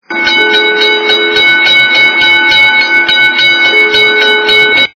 При прослушивании Звук - Предупреждение о прибывающем поезде качество понижено и присутствуют гудки.
Звук Звук - Предупреждение о прибывающем поезде